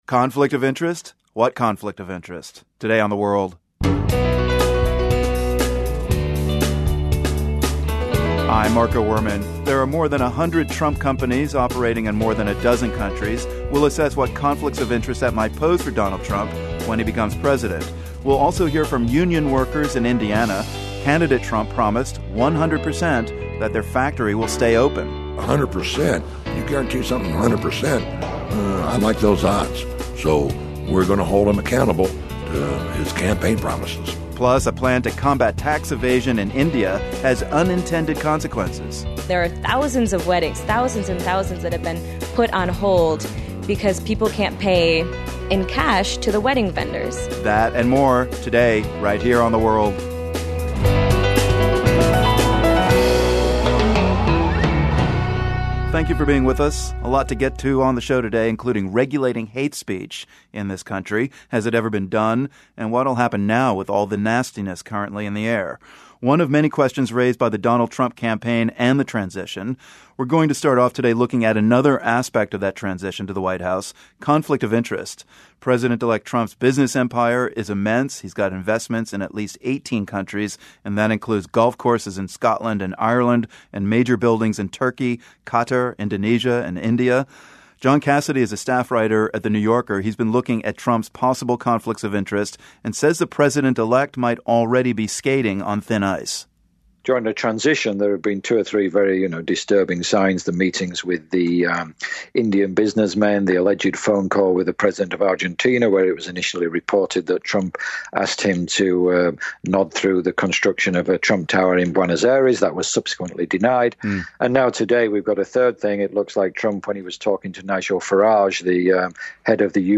Donald Trump says he'll tear up trade deals and bring jobs back to the US — today we'll hear from factory workers in Indiana about how much faith they have that the president-elect will deliver. In India, the government has discontinued the use of the most widely circulated bank notes in an attempt to tackle tax evasion, but it's hurting people who don't have bank accounts — many of them women. Plus, we hear about the health consequences from all the smoke coming from the massive oil field fires that ISIS set before they left northern Iraq.